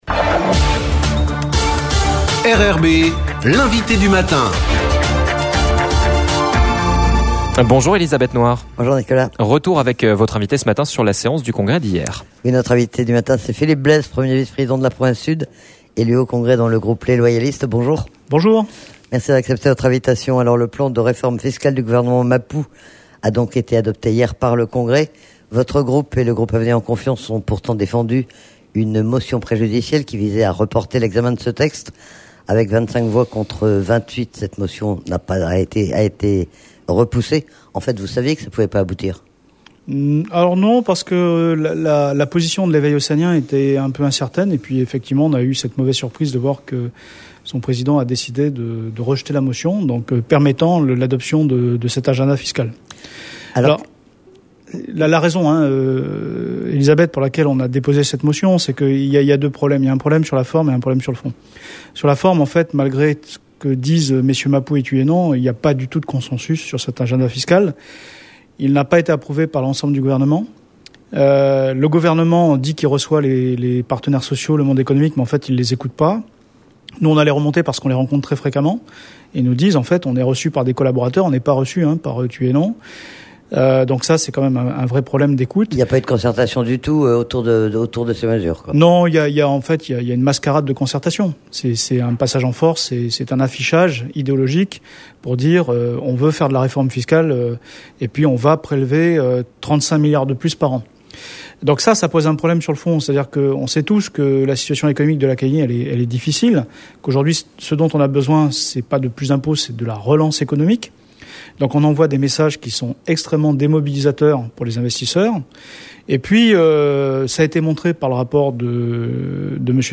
reçoit Philippe Blaise, premier vice-président de la province sud et élu du groupe Les Loyalistes au congrès